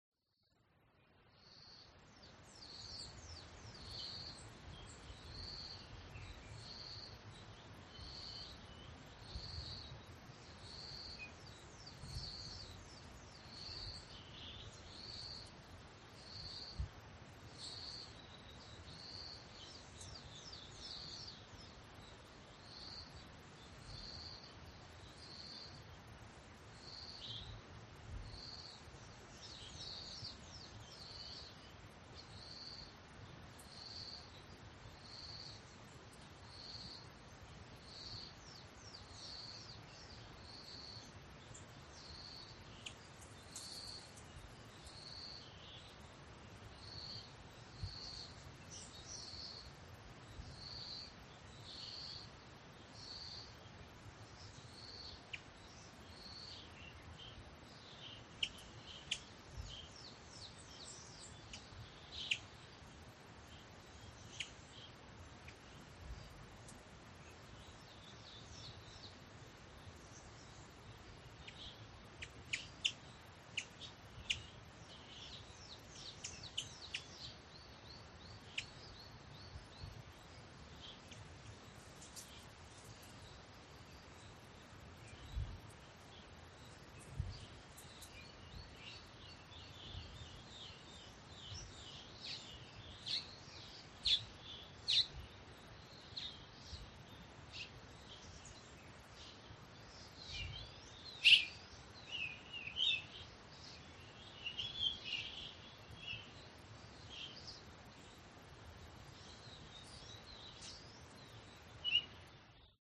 Здесь собраны натуральные записи солнечных дней: легкий ветер в кронах деревьев, стрекотание кузнечиков, плеск воды и другие уютные звучания.
Шепот прохлады ветра в летнее утро